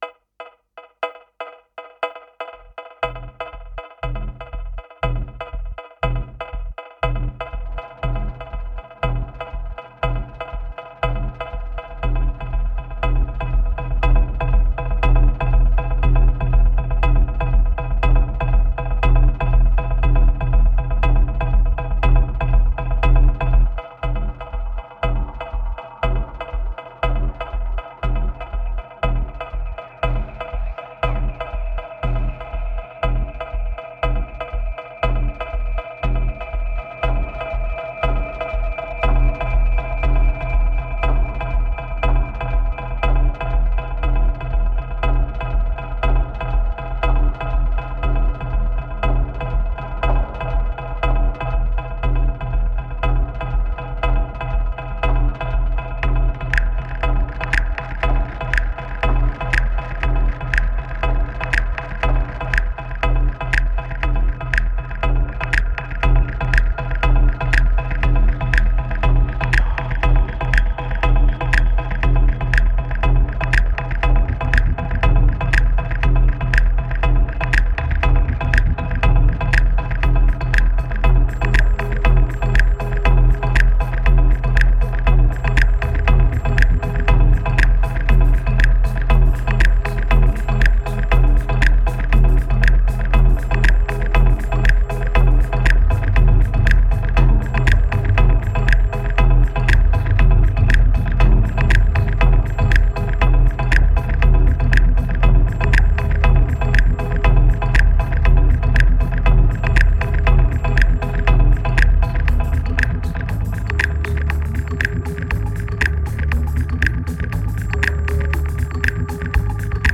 2257📈 - 69%🤔 - 120BPM🔊 - 2011-04-09📅 - 138🌟